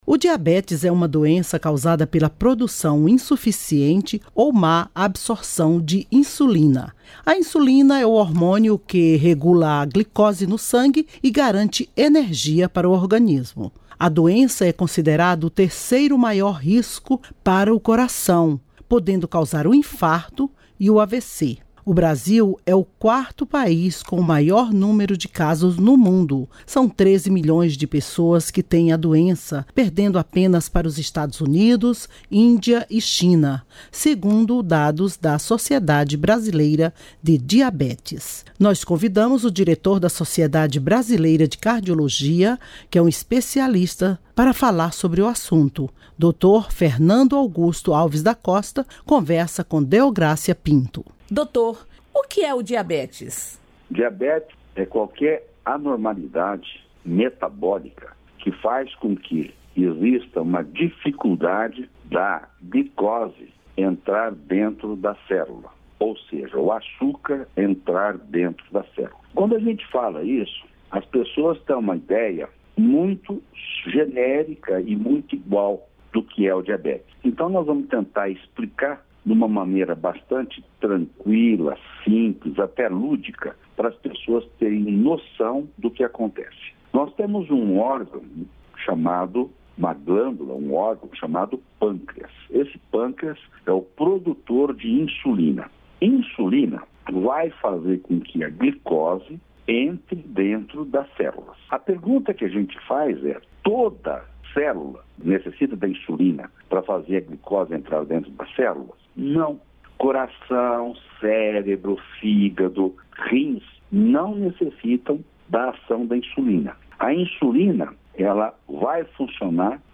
Neste Dia Mundial do Diabetes, especialista fala sobre causas e sintomas da doença